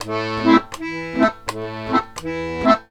Index of /90_sSampleCDs/USB Soundscan vol.40 - Complete Accordions [AKAI] 1CD/Partition A/01-80ADONOL